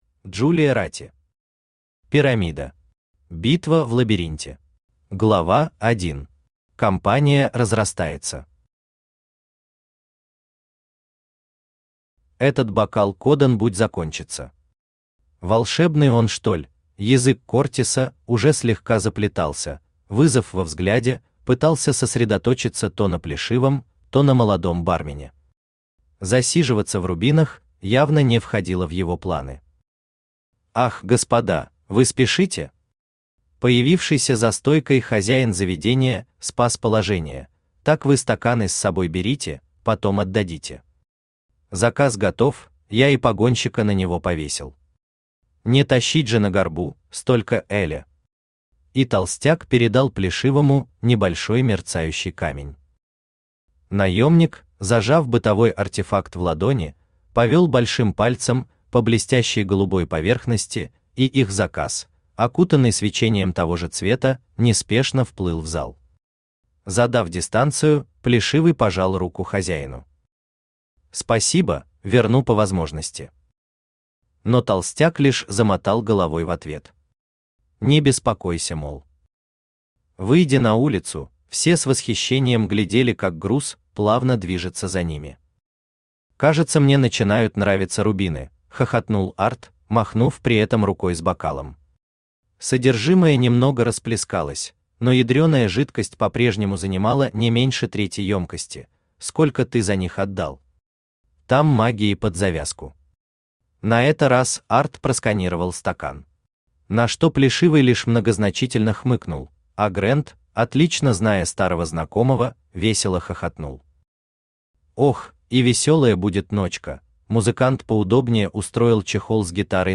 Аудиокнига Пирамида. Битва в Лабиринте | Библиотека аудиокниг
Битва в Лабиринте Автор Джулия Рати Читает аудиокнигу Авточтец ЛитРес.